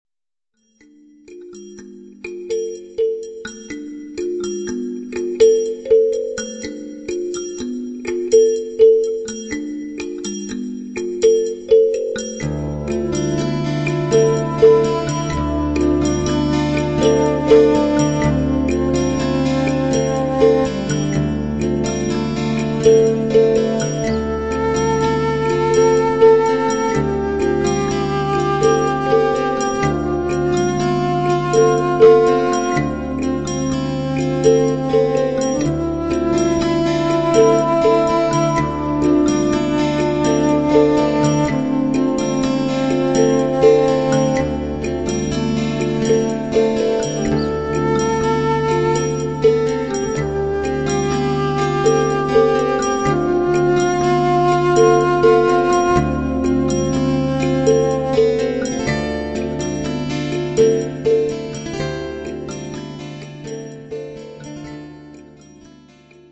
fiddle
bass
drums/perc.
guitar&percussion